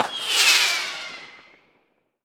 rocket.mp3